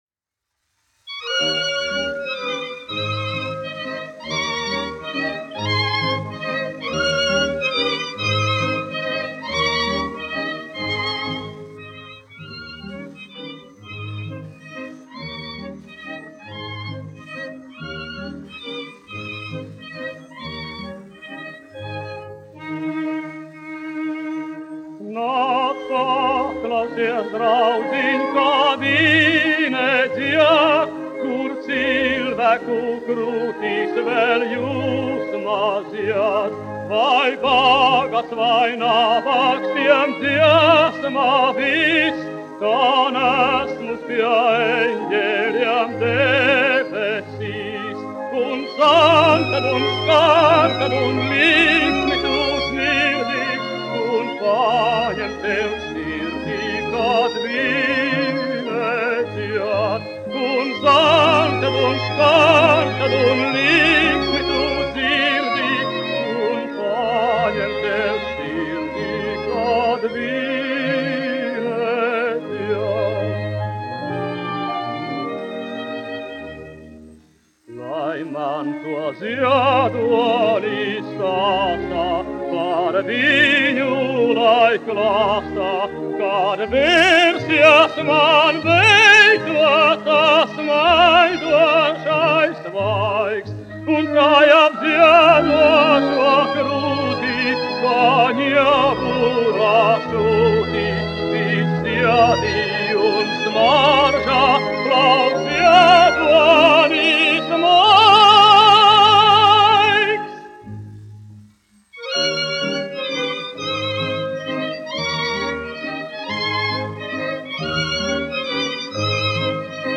Priednieks-Kavara, Artūrs, 1901-1979, dziedātājs
1 skpl. : analogs, 78 apgr/min, mono ; 25 cm
Operetes--Fragmenti